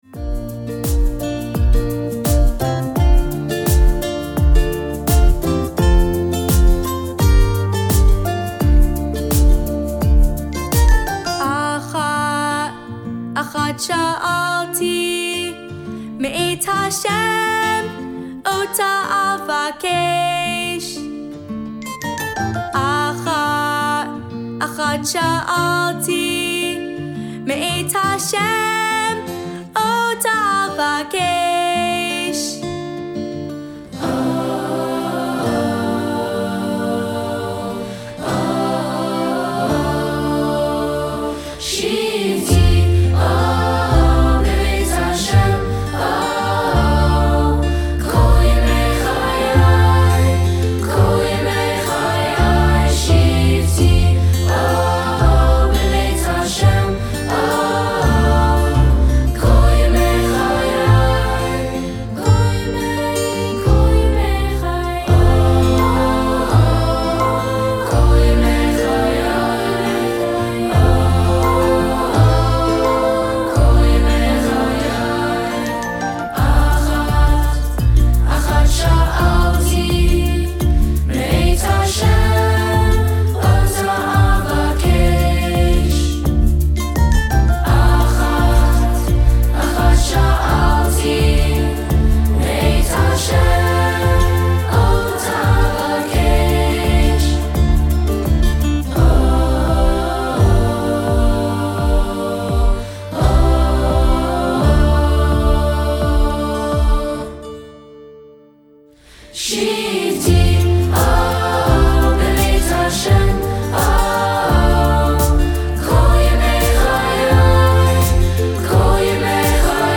Genre: Religious
Contains solos: Yes